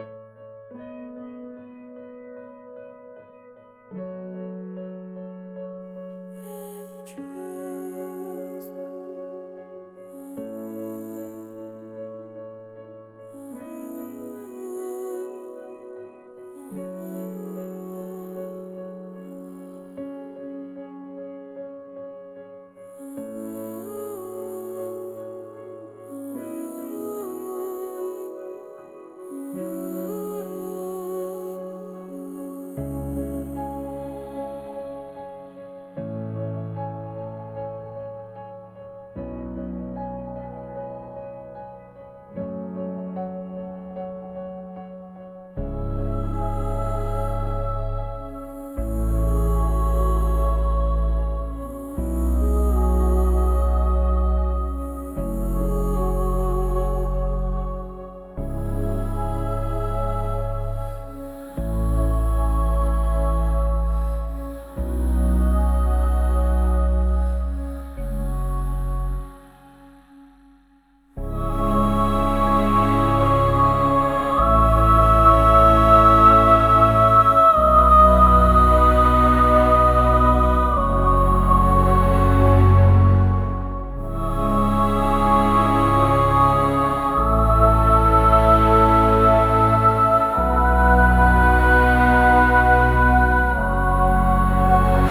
Back Ground Music